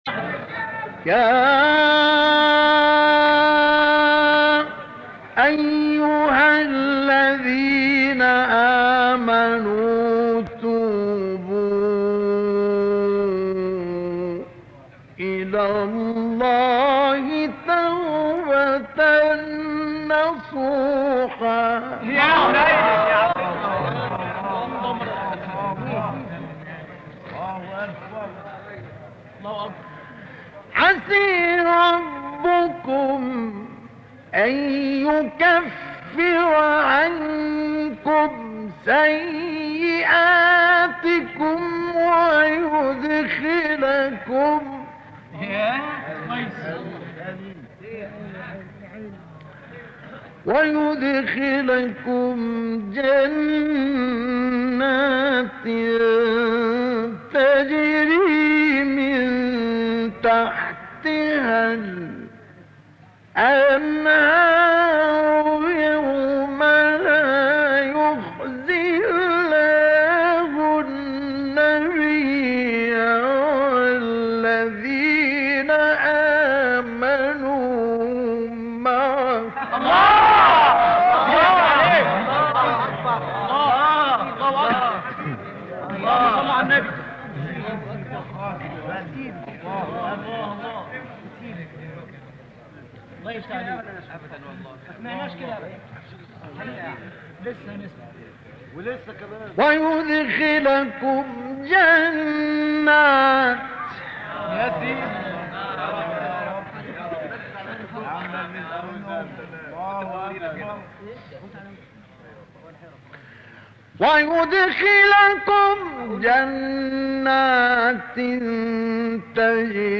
گروه شبکه اجتماعی: تلاوت‌های متفاوت آیه 8 سوره مبارکه تحریم با صوت مصطفی اسماعیل را می‌شنوید.
مقطعی از تلاوت در سال 1986 در محفل تاریخی در الترعه